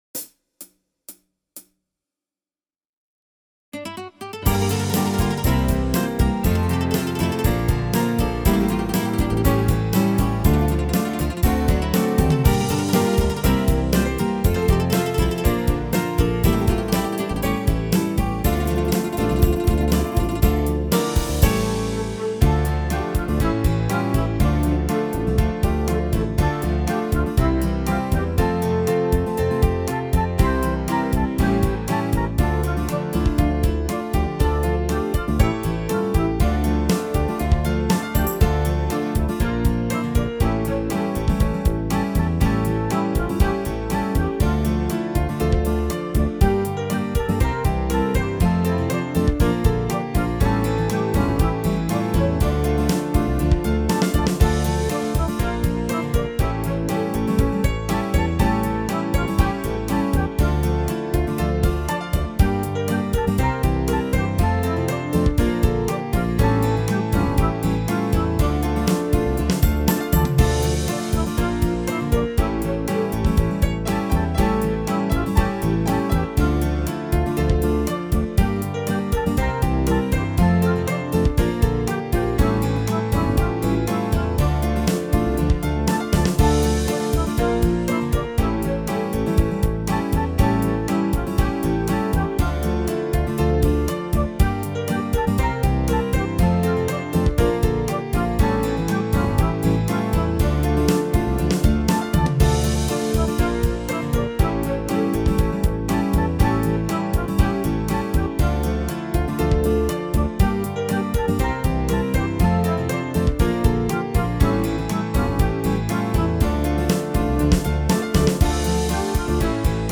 MIDI file instrumental version